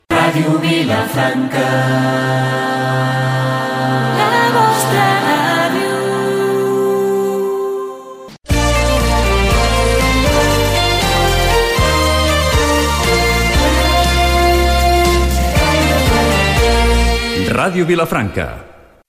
Dos indicatius de l'emissora